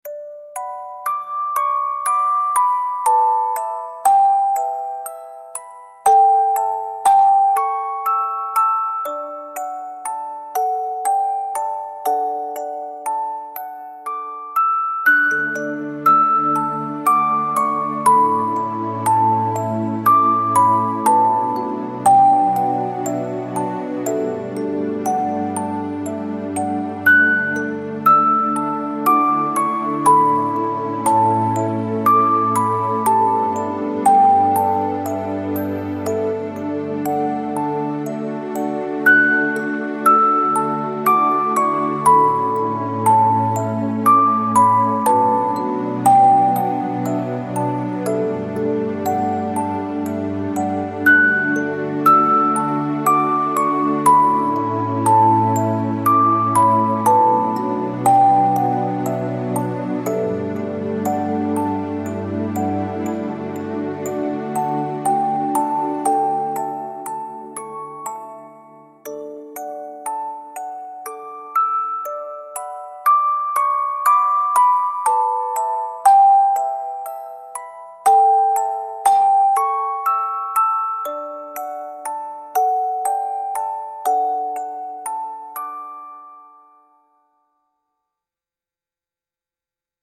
gentle music box melody layered with soft strings and ambient pads